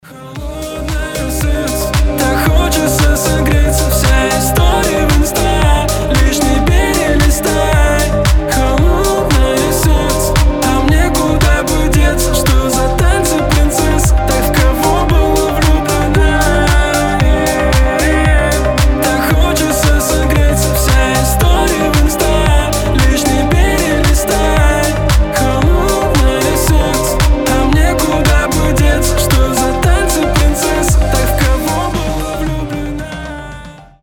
• Качество: 320, Stereo
лирика
грустные